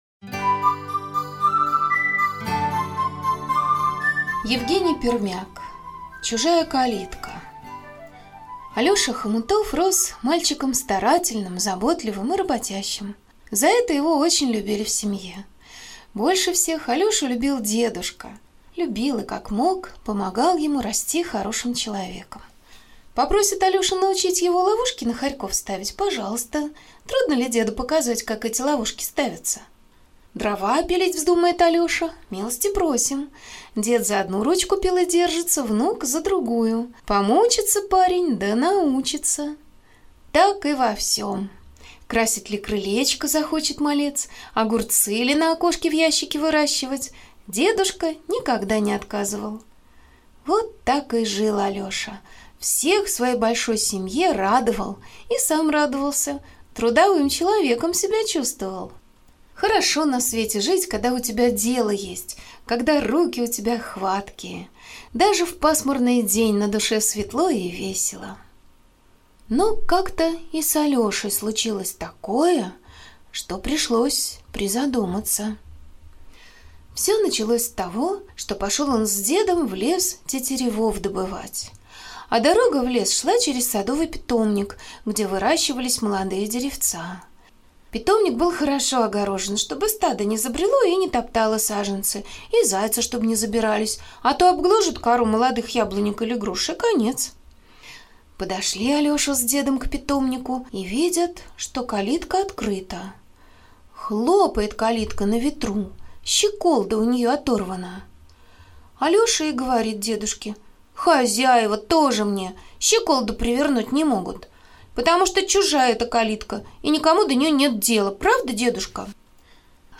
Аудиорассказ «Чужая калитка»